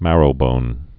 (mărō-bōn)